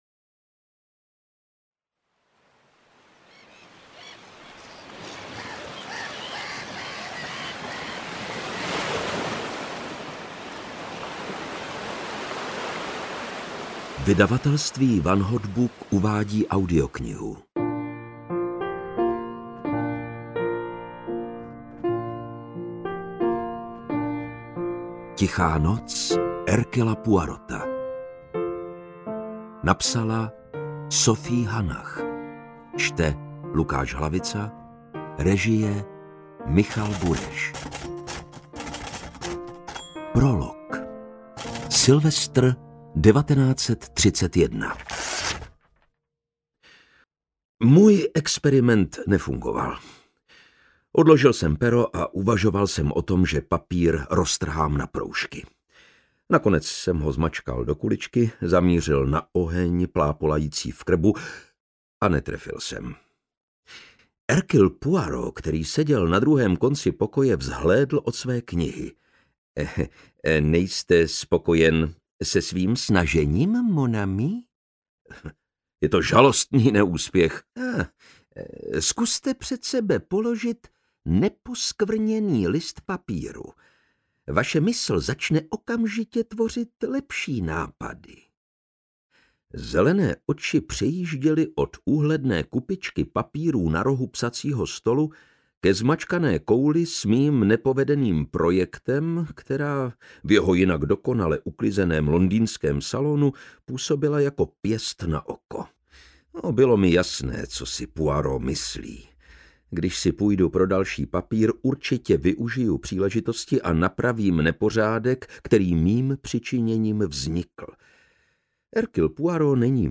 Interpret:  Lukáš Hlavica
AudioKniha ke stažení, 38 x mp3, délka 11 hod. 14 min., velikost 619,5 MB, česky